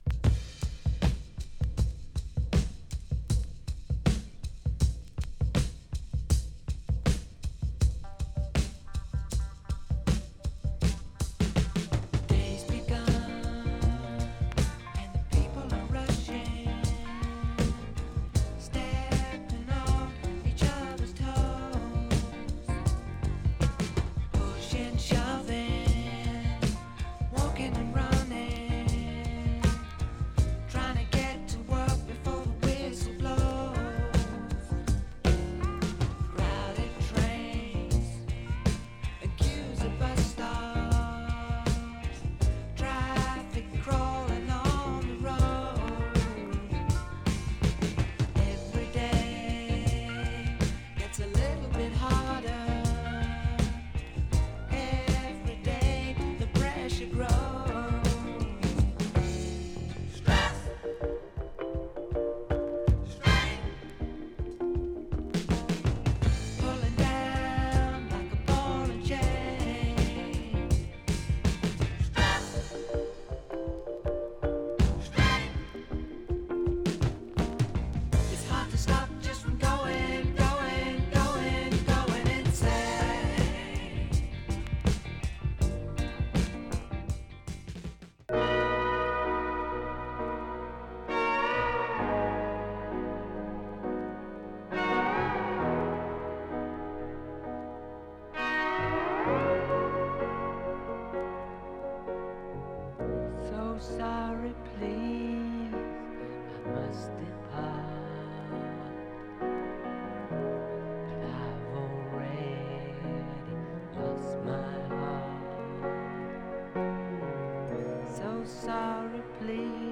レアグルーヴ方面からも人気のファンクなブレイク入り